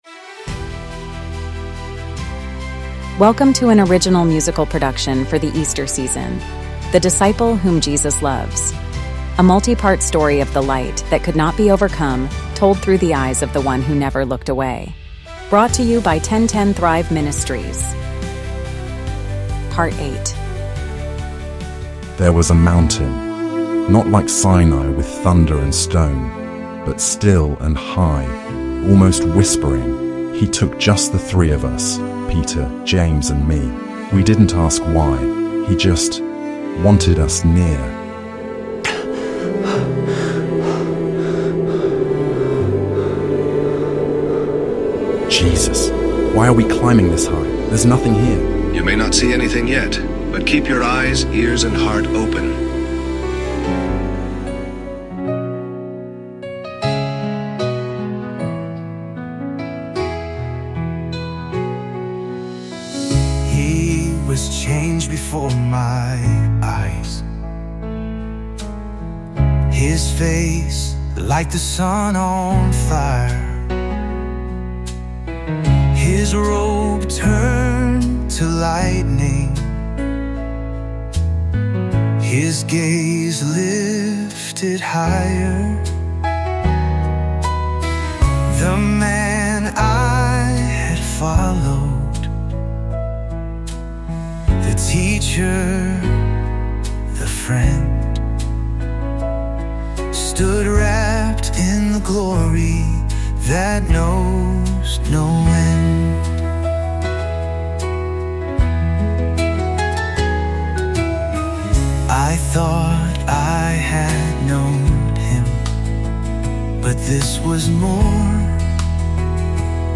podcast musical